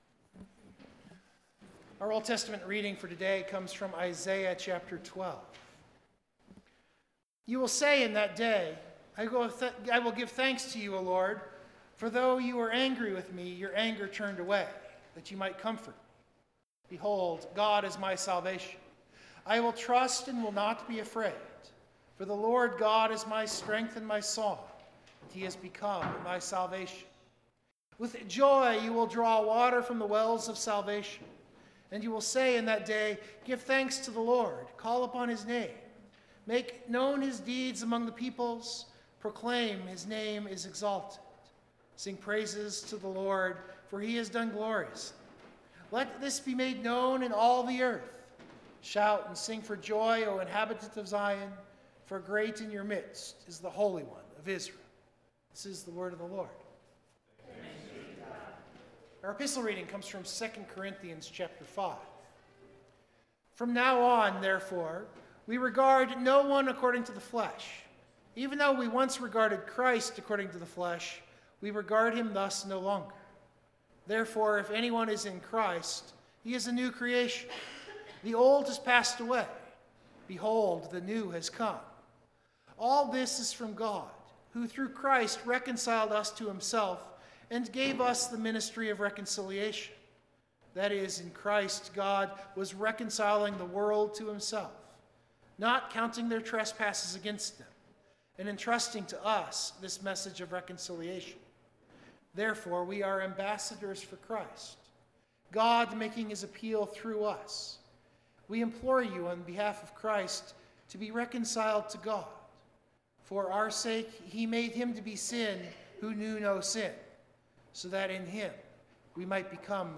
The sermon looks at these themes in the text and pulls out three applications to our lives. The hymn of the day included in the recording and reflected at places in the sermon is Luther’s A Mighty Fortress with its themes of spiritual warfare against the strong man and what Christ has already done to bring us near.